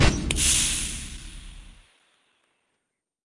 描述：使用来自freesound.Far范围声音的声音重新制作光环needler步枪
Tag: 科幻 激光 刺针